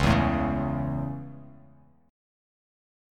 Dbm#5 chord